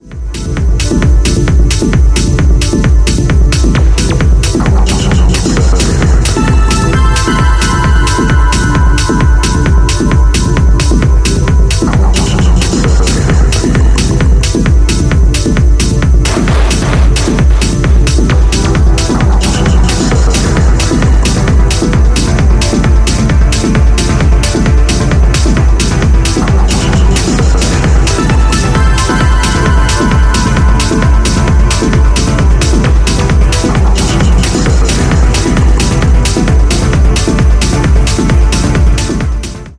B面と対比するかのような荘厳なコードで魅せるピークタイム路線の内容です。